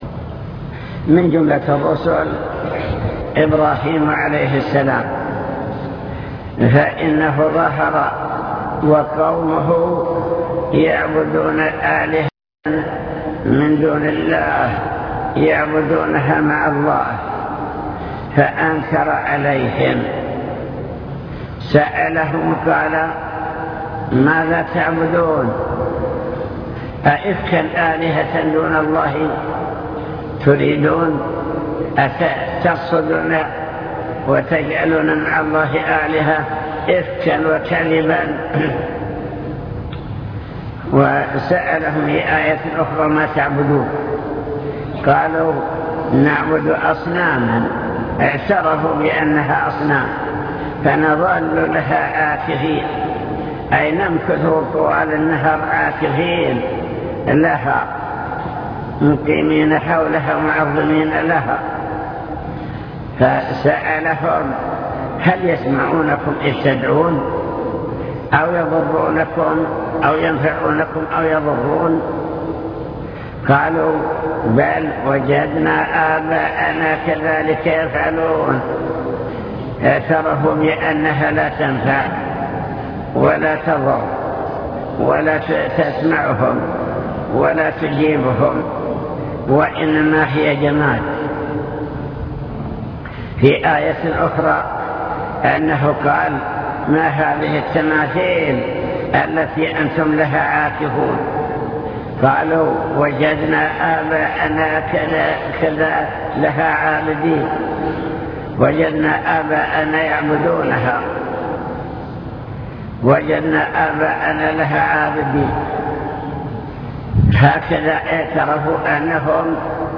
المكتبة الصوتية  تسجيلات - محاضرات ودروس  محاضرة في جامع حطين دعوة الأنبياء والرسل